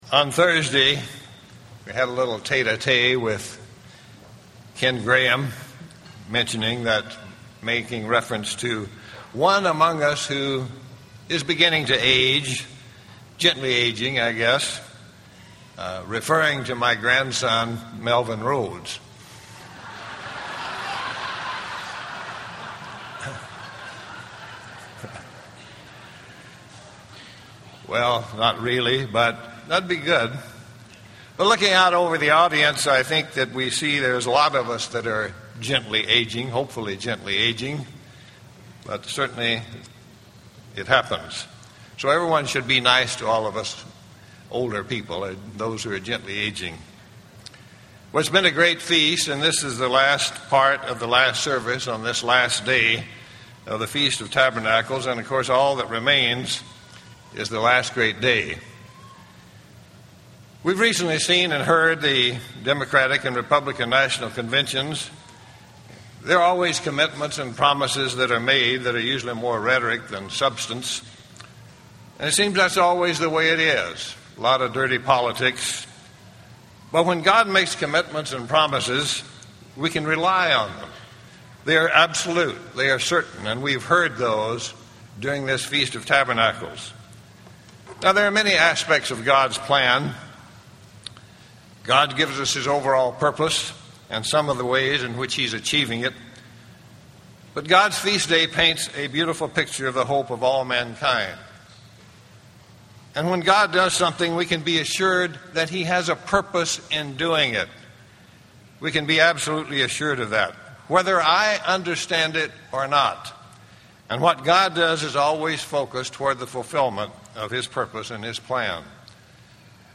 This sermon was given at the Wisconsin Dells, Wisconsin 2008 Feast site.